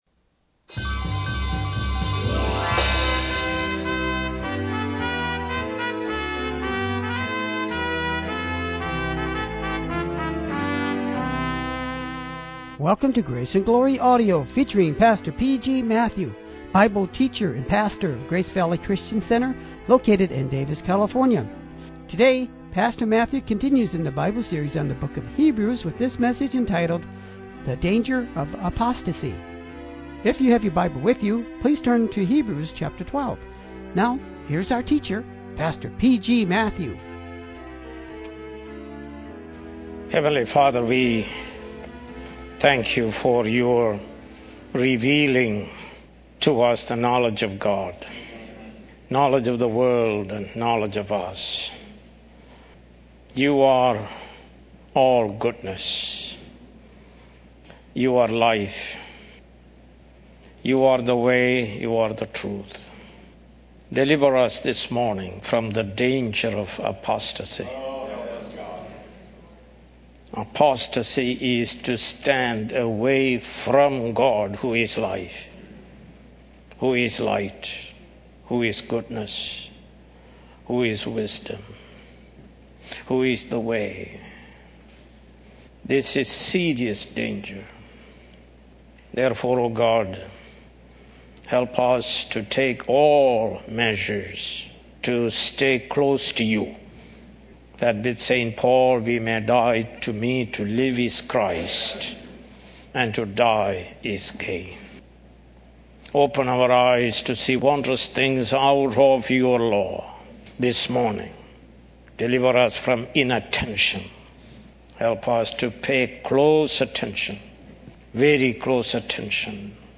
More Sermons From the book of Hebrews